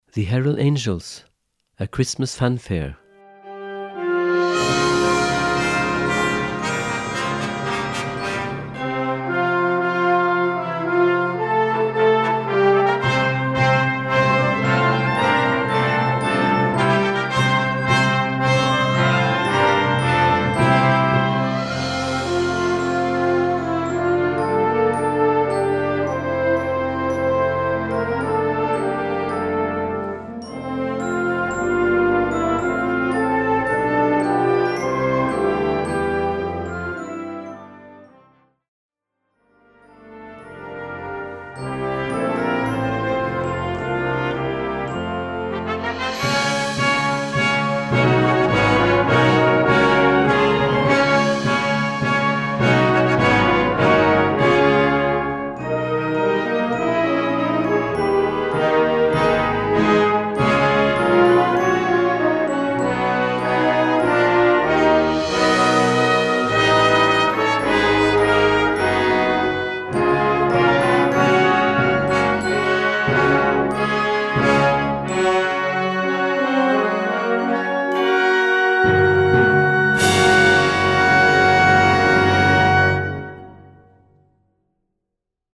Gattung: Weihnachtsmusik
Besetzung: Blasorchester
This christmas fanfare